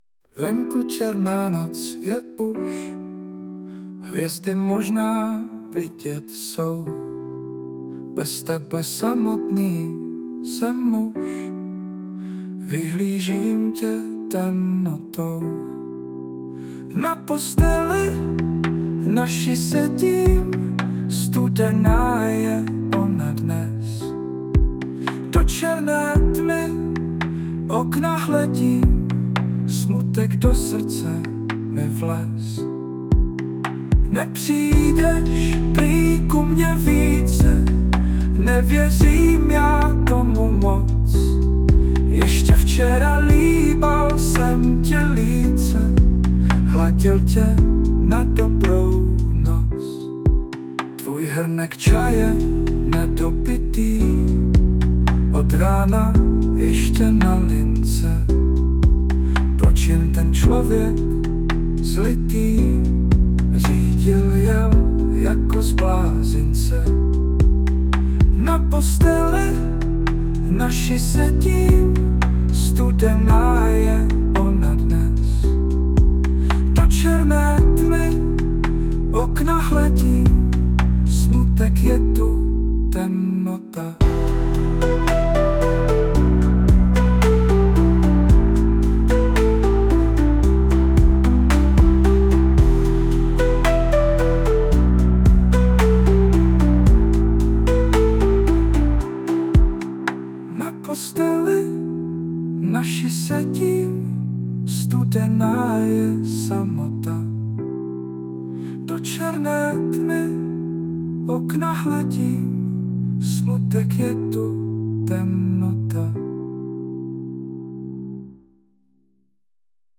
Smutné
* hudba, zpěv: AI